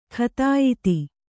♪ khatāyiti